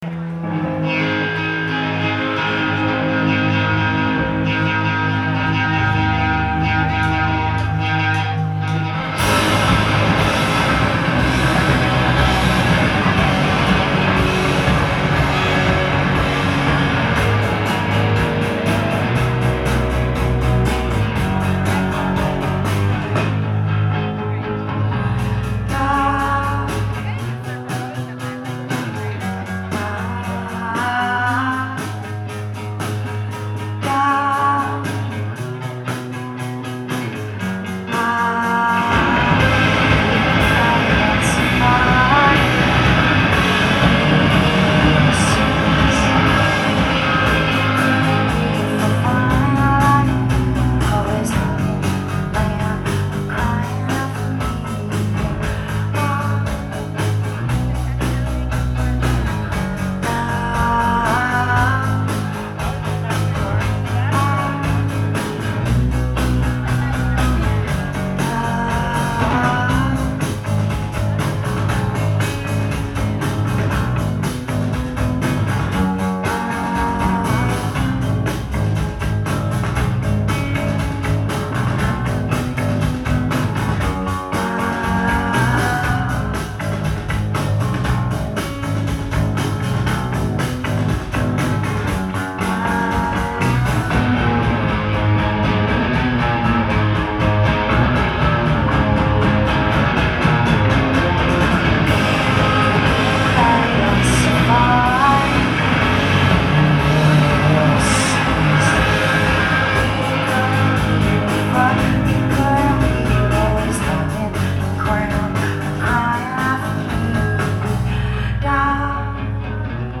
Live at the Paradise
in Boston, MA